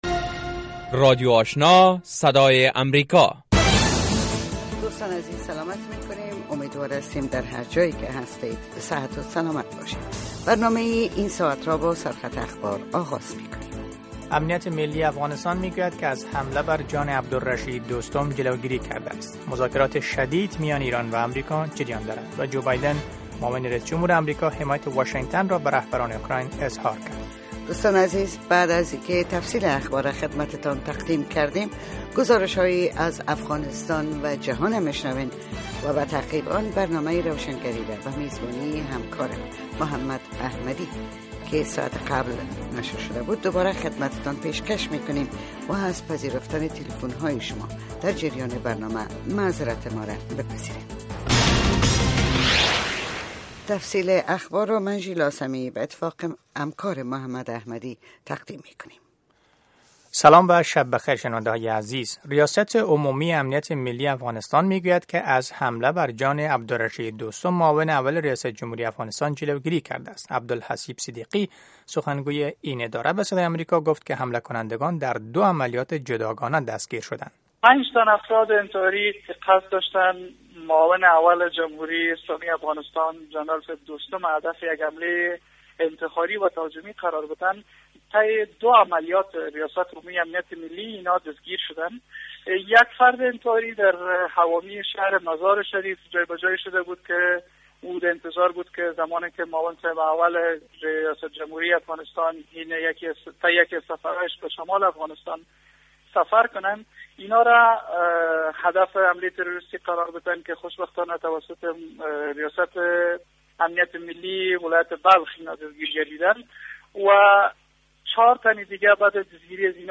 سومین برنامه خبری شب